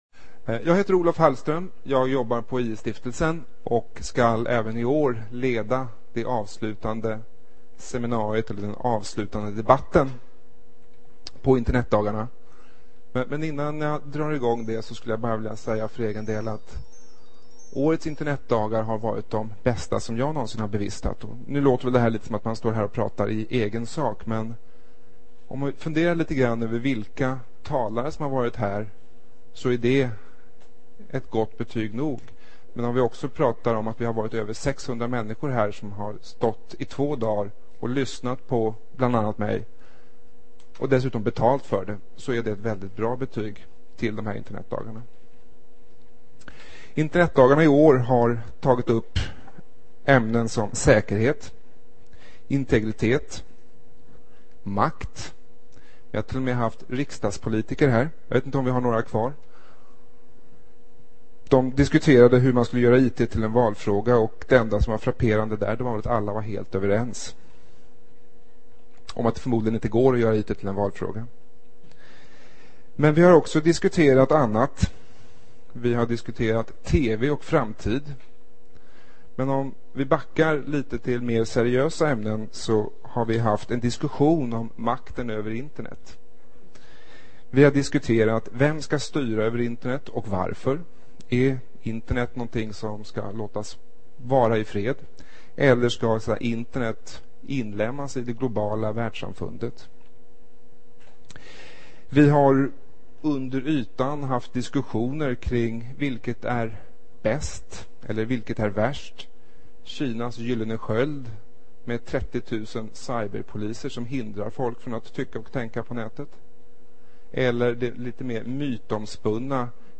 32-debatt.mp3